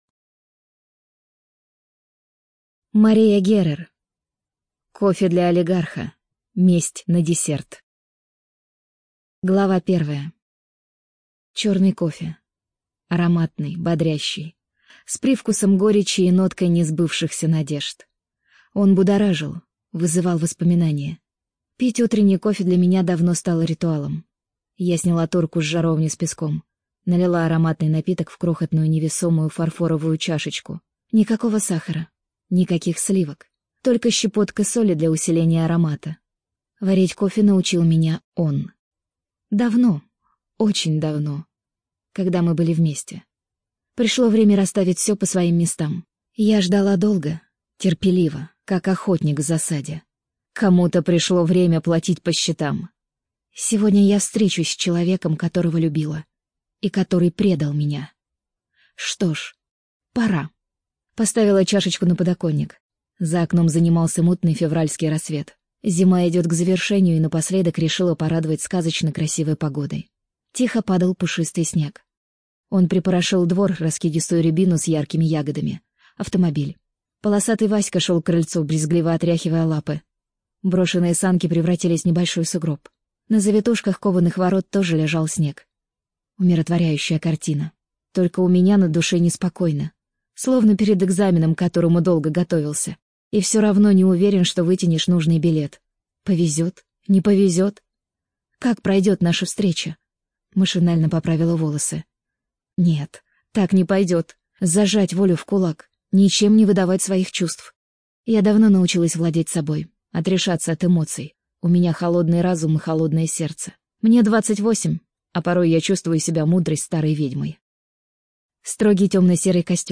ЖанрЛюбовная проза